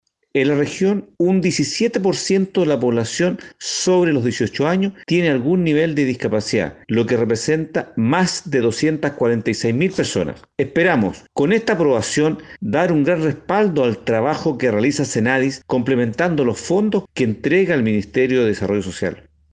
Al respecto, el consejero regional, Manuel Millones indicó que la iniciativa de entregar más recursos al Senadis, se estaba tramitando hace varios meses, debido a que en la zona hay un 17% de personas mayores de edad que presentan algún nivel de discapacidad.